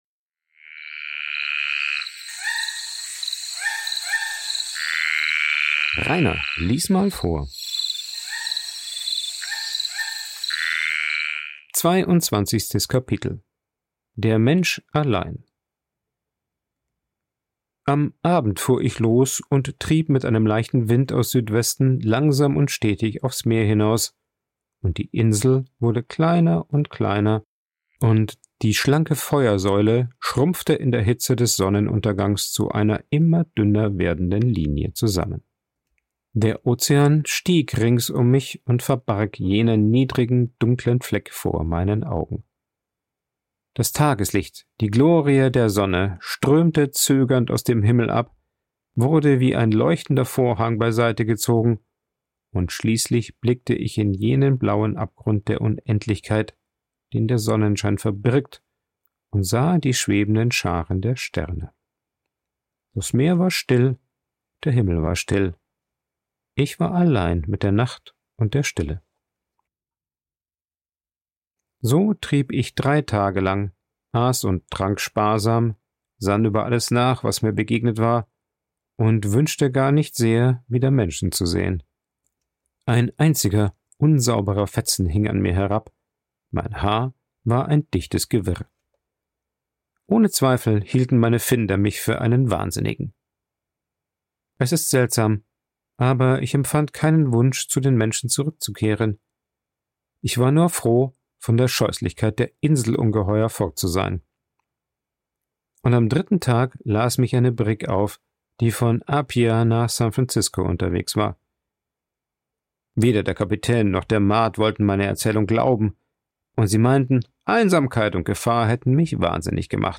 Ein Vorlese Podcast
Coworking Space Rayaworx, Santanyí, Mallorca.